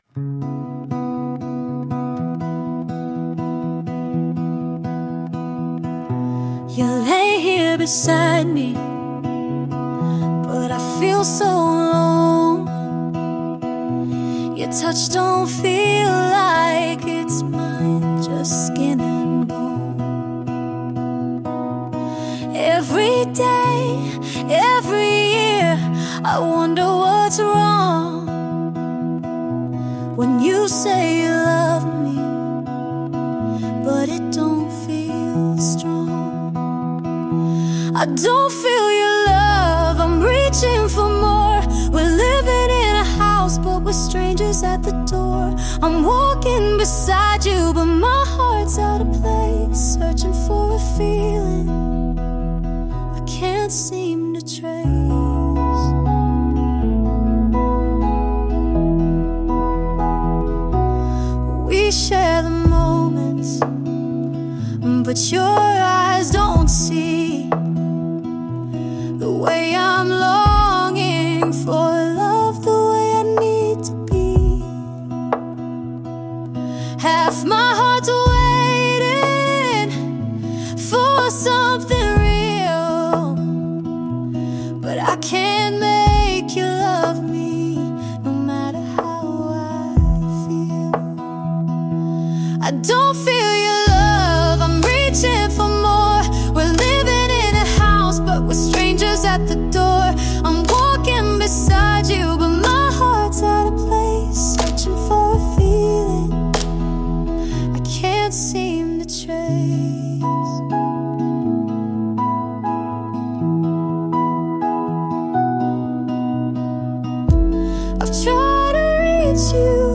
Whispery yet strong.
The quality is amazing.
What a passionate song!